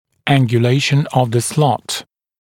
[ˌæŋgju’leɪʃən əv ðə slɔt][ˌэнгйу’лэйшэн ов зэ слот]наклон паза (брекета), ангуляция паза (брекета)